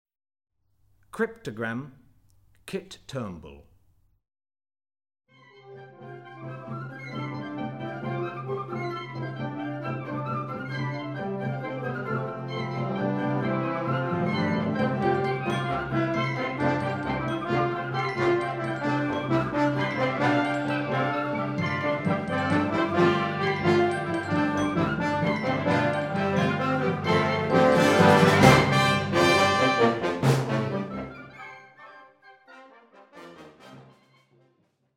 Voicing: Wind Band